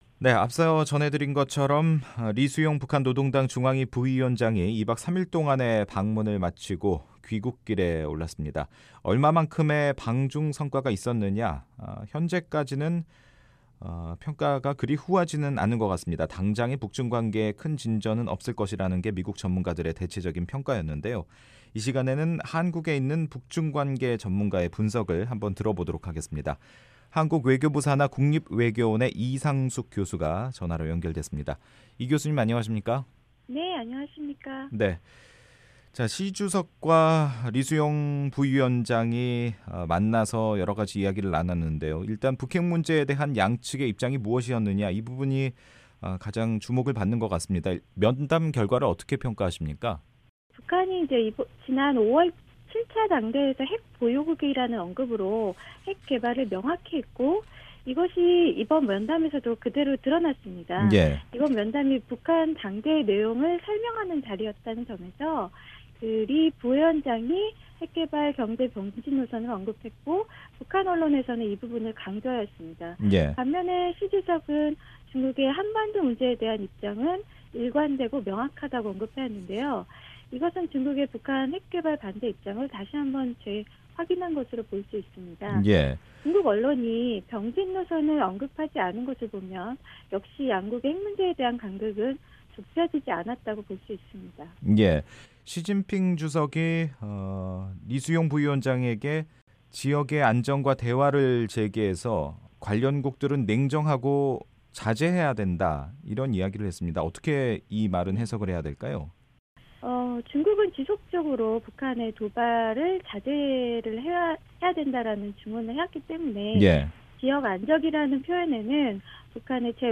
[인터뷰